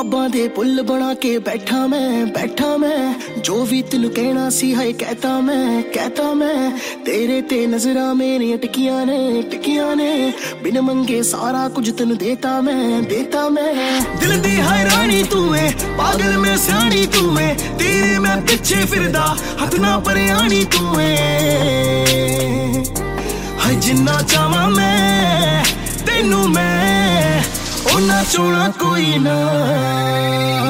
Category: Punjabi Ringtones